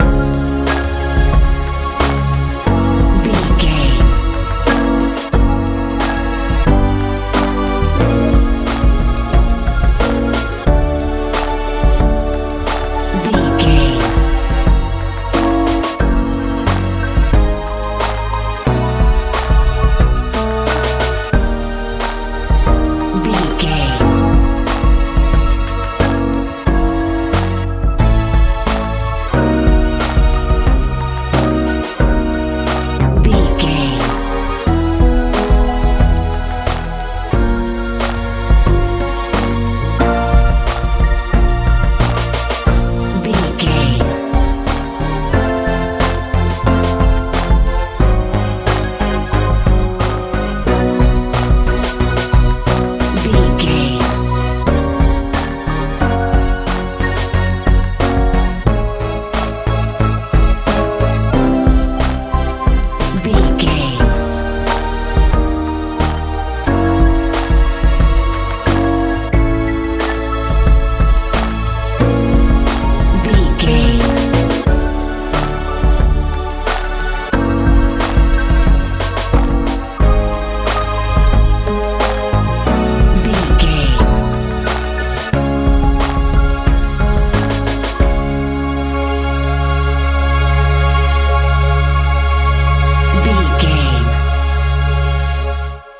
hip hop feel
Ionian/Major
B♭
optimistic
piano
synthesiser
bass guitar
drums
80s
90s